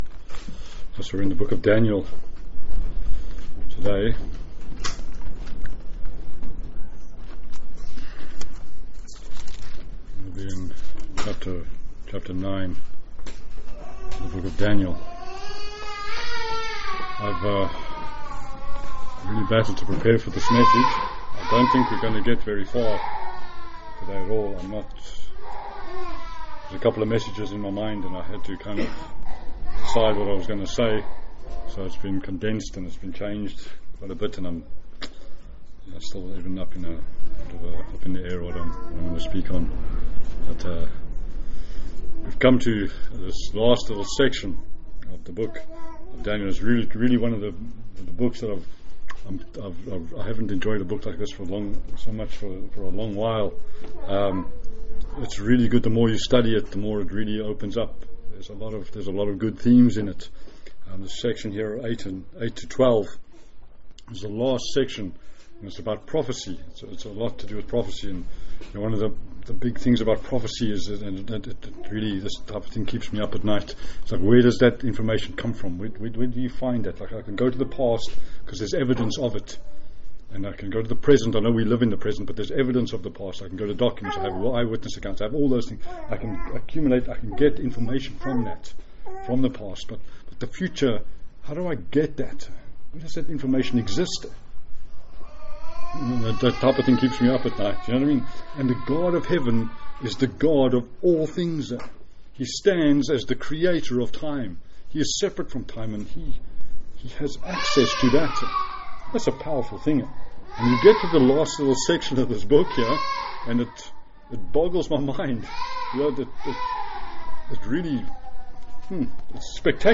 A message from the series "Daniel."